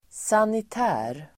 Ladda ner uttalet
Uttal: [sanit'ä:r]